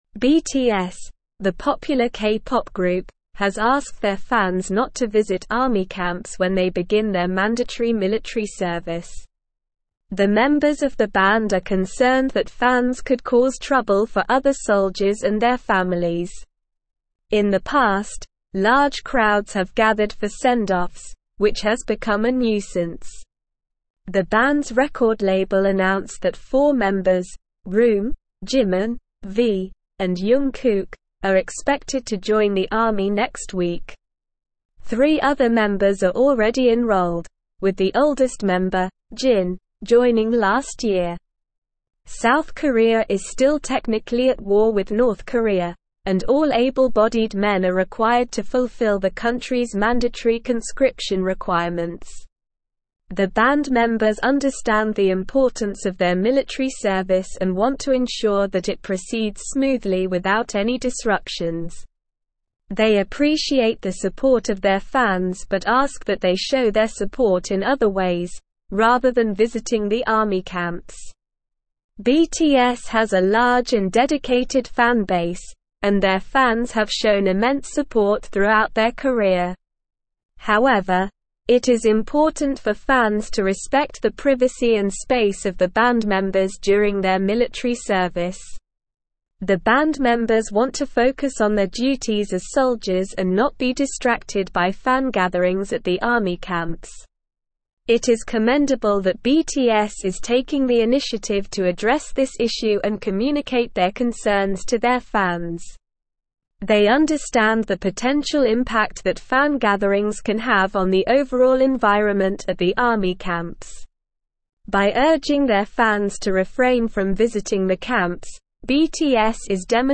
Slow
English-Newsroom-Advanced-SLOW-Reading-BTS-Urges-Fans-to-Stay-Away-from-Army-Camps.mp3